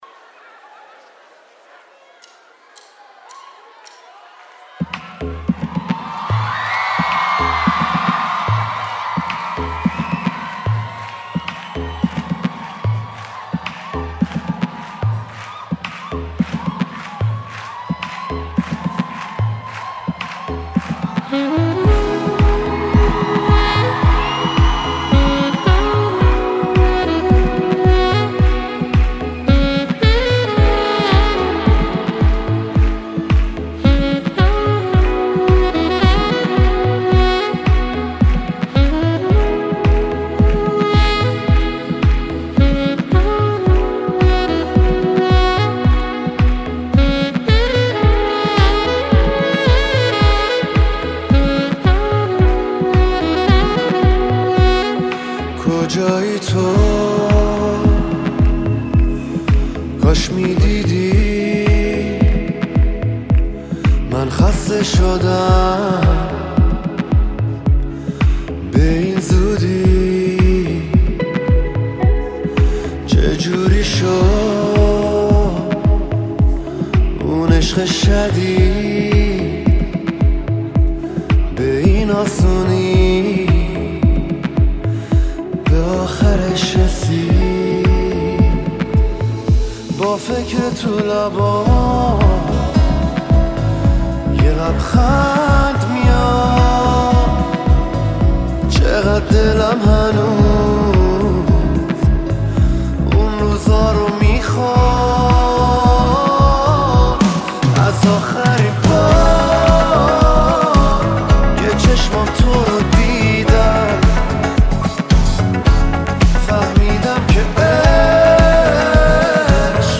( اجرای زنده )
(Live)